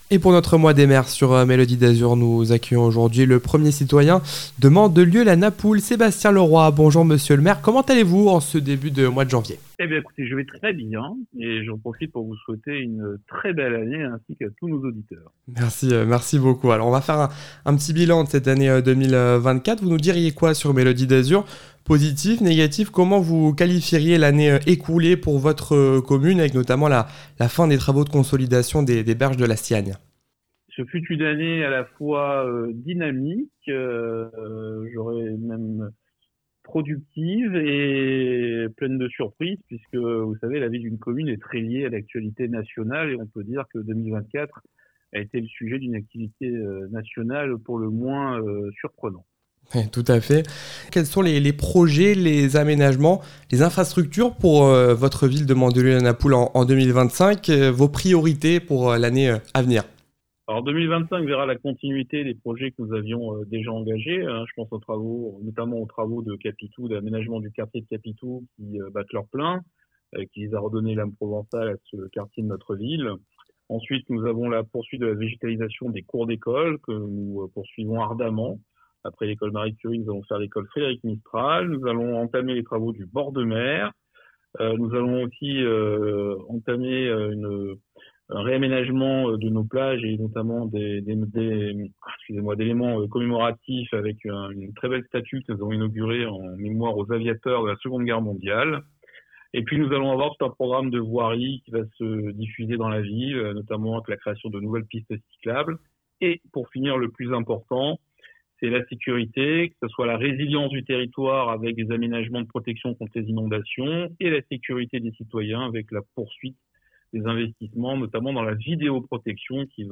Interview des Maires - Episode 8 : Mandelieu-La Napoule avec Sebastien Leroy